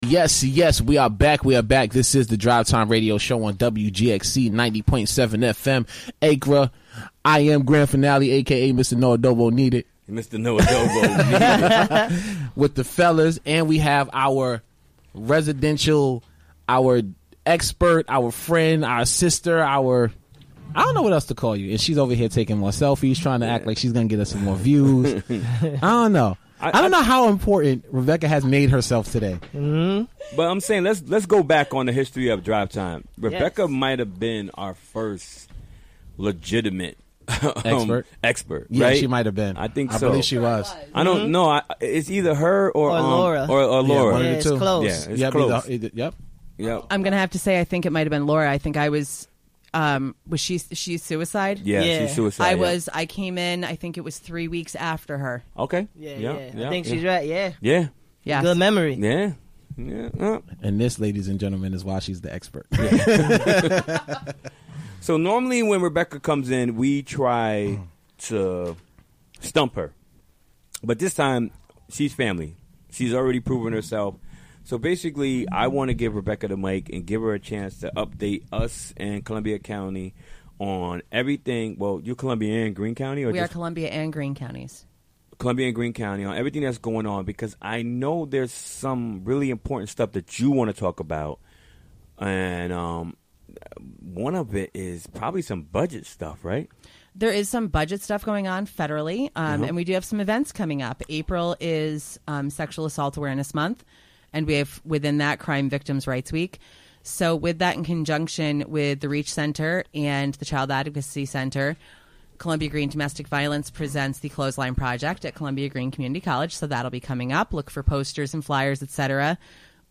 Recorded during the WGXC Afternoon Show Wednesday, March 22, 2017.